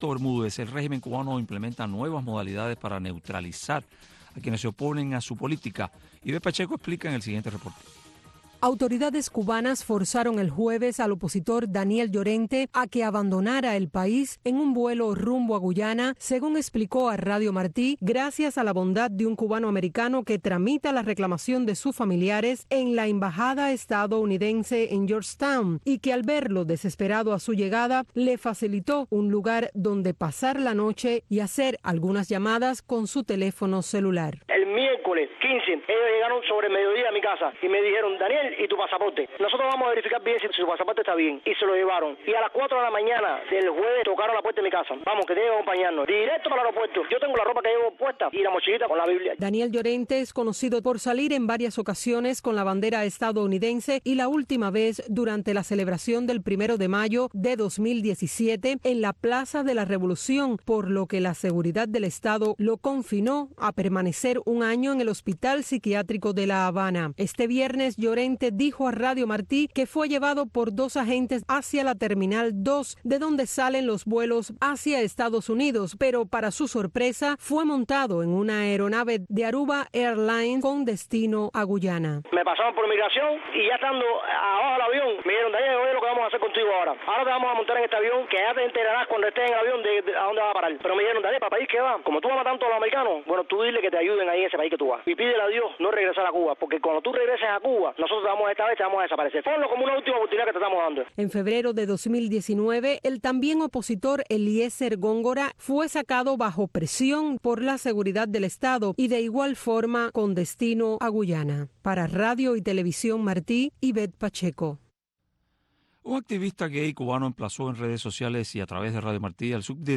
“Ventana a Miami”, un programa conducido por el director de la oficina de transmisiones hacia Cuba, Tomás Regalado, te invita a sintonizarnos de lunes a viernes a la 1:30 PM en Radio Martí. “Ventana a Miami” te presenta la historia de los cubanos que se han destacado en el exilio para que tú los conozcas.